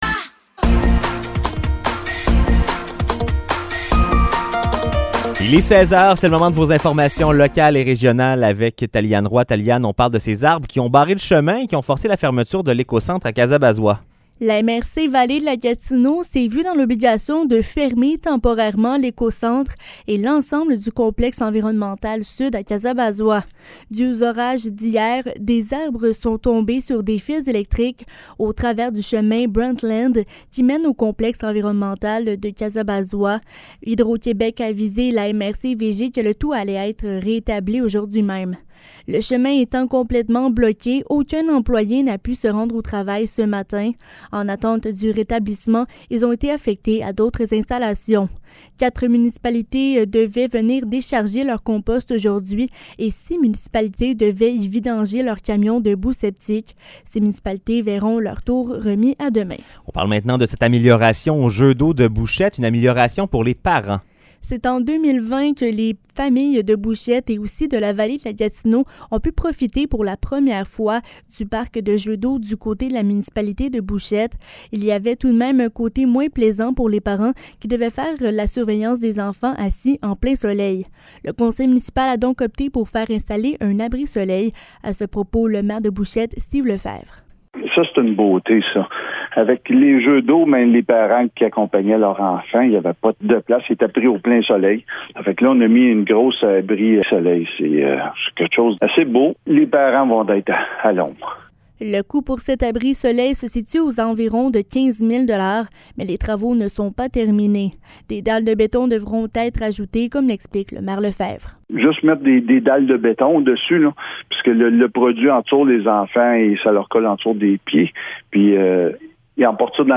Nouvelles locales - 27 juillet 2023 - 16 h